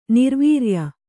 ♪ nirvīrya